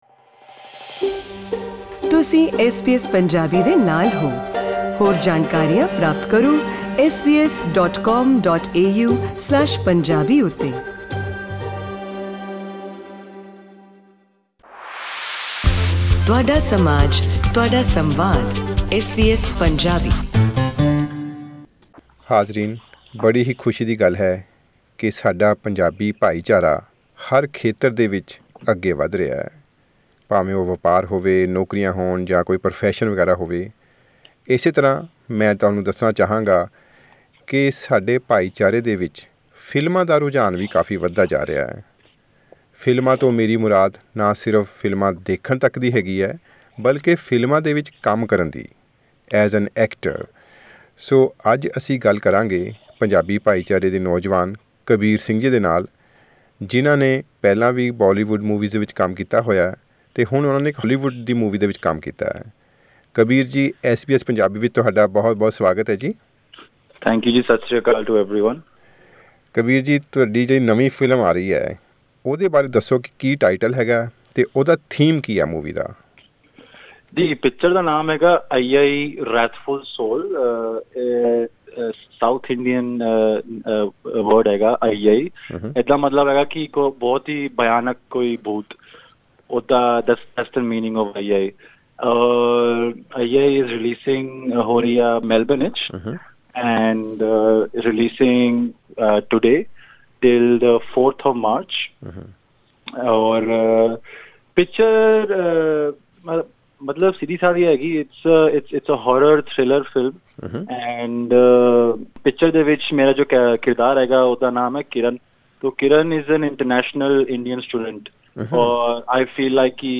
‘Aiyai – a wrathful soul,' is an Australian movie that pivots around the struggle of an Indian student in Brisbane, who is tormented by the tragedies experienced by people around him. In an interview